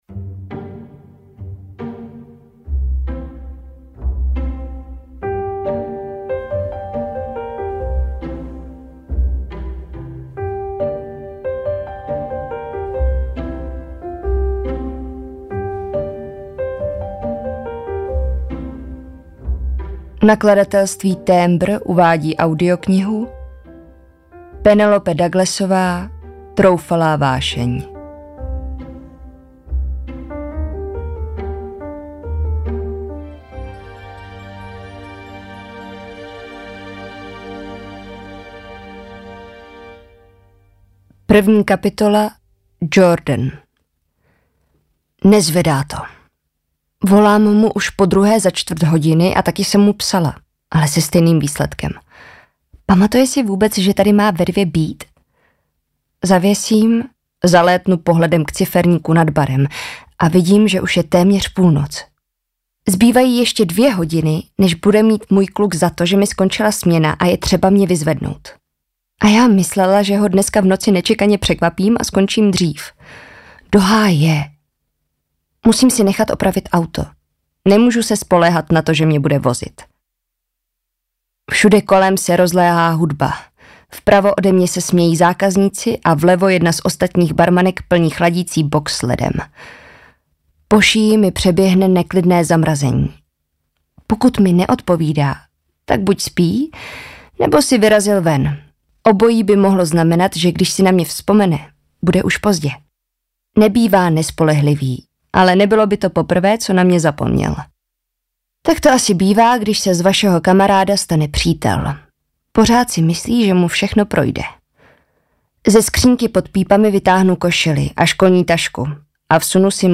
Troufalá vášeň audiokniha
Ukázka z knihy
Natočeno ve studiu Chevaliere